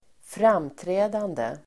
Uttal: [²fr'am:trä:dande]